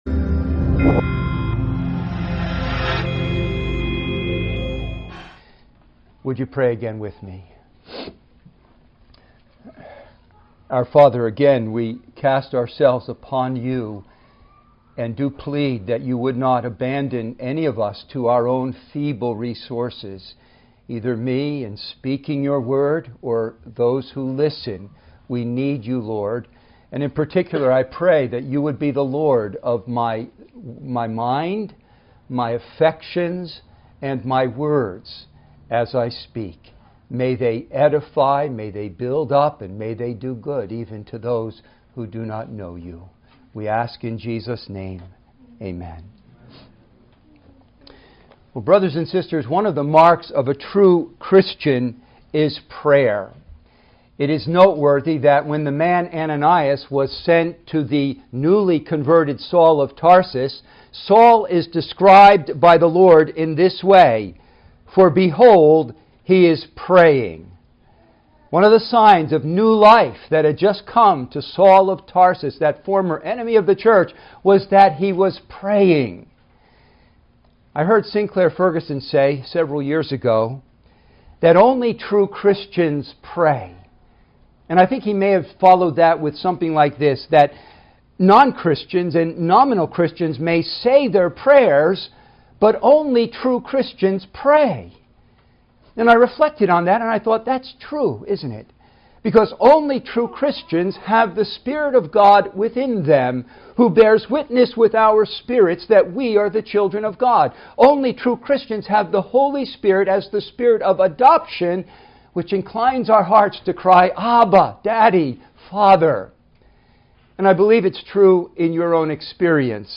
Full Sermons